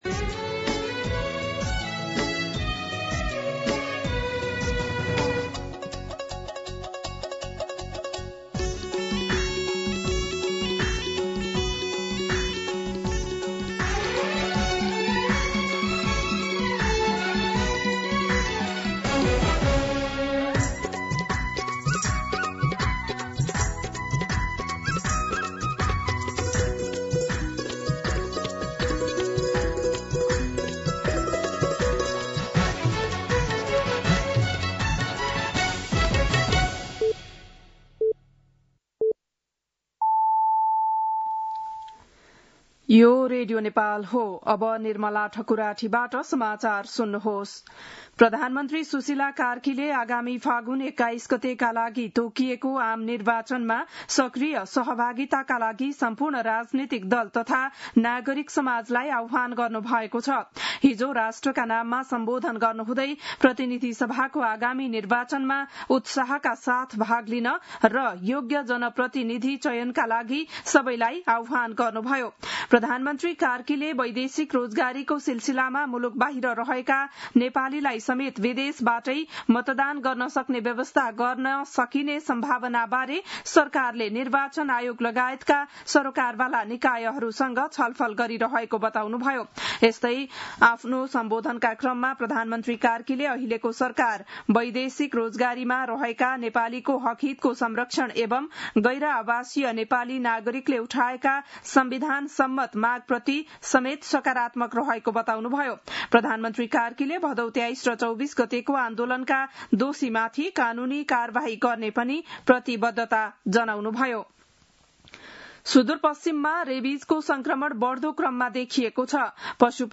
बिहान ११ बजेको नेपाली समाचार : १० असोज , २०८२